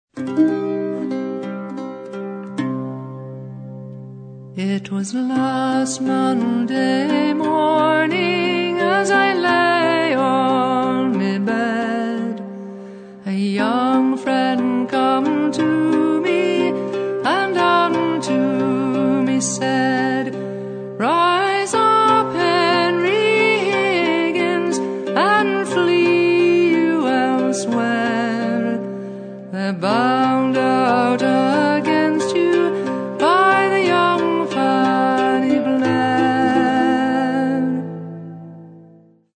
First part, 0:38 sec, mono, 22 Khz, file size: 192 Kb.